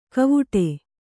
♪ kavuṭe